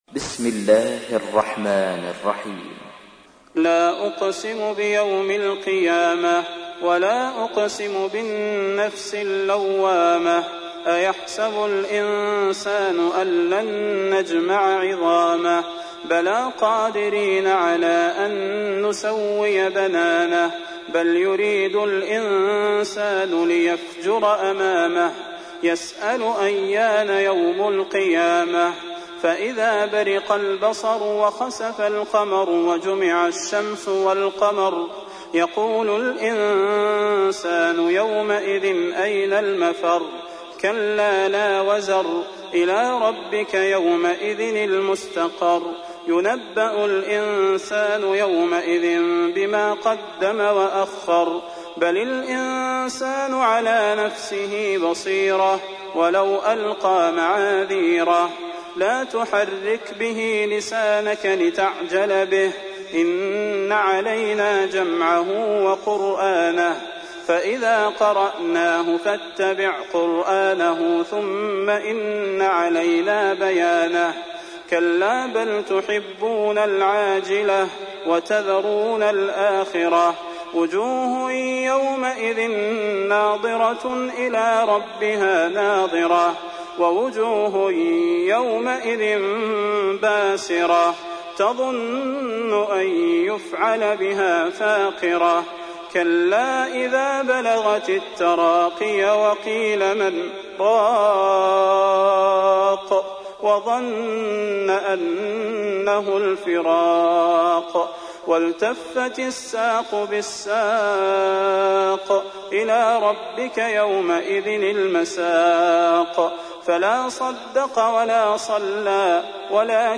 تحميل : 75. سورة القيامة / القارئ صلاح البدير / القرآن الكريم / موقع يا حسين